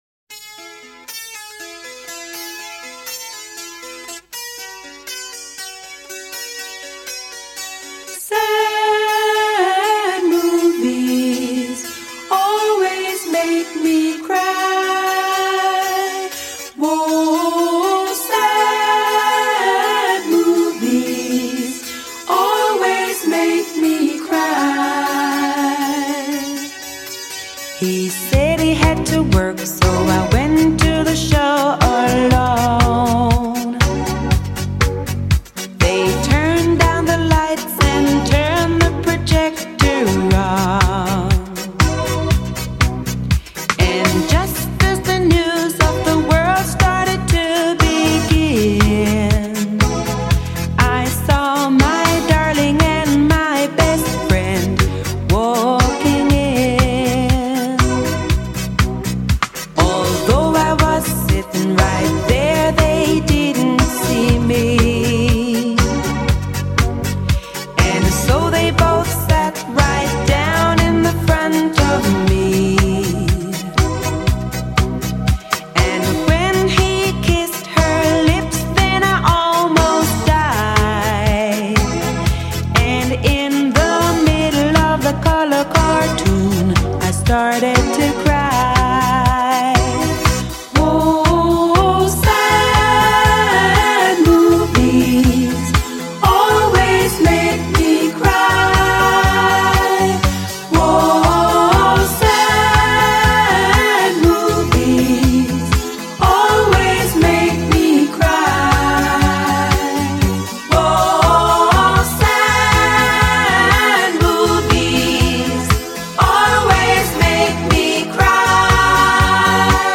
专辑风格：迪斯科